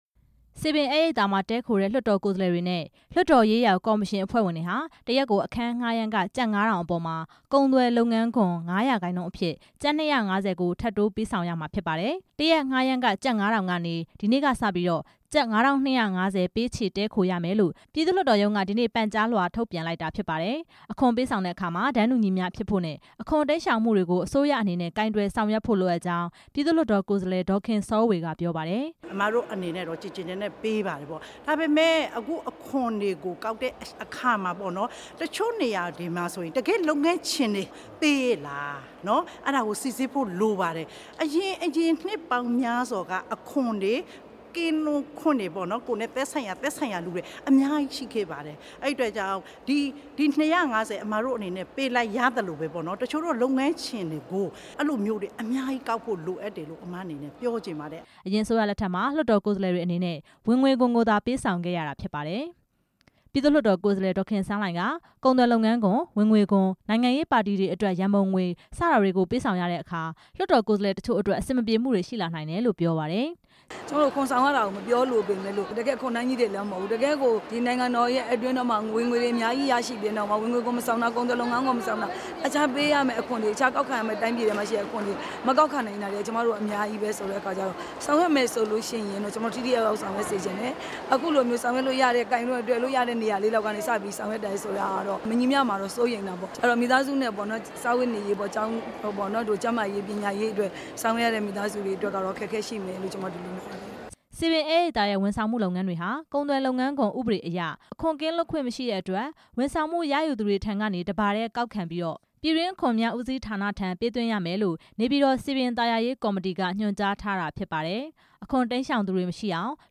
တစ်ရက် အခန်းငှားရမ်းခ ငွေကျပ် ၅ဝဝဝ အပေါ်မှာ ငါးရာခိုင်နှုန်း ၂၅၀ ကျပ်ကို ပေးဆောင်ရမှာဖြစ်တယ် လို့ ပြည်သူ့လွှတ်တော်ကိုယ်စားလှယ် ဒေါ်ခင်စောေ၀ က ပြောပါတယ်။